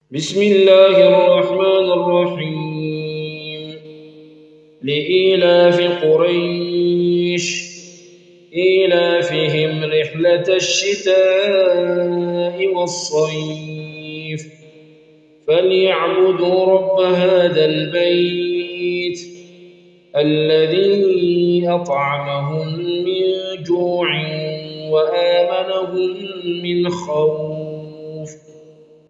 قراءة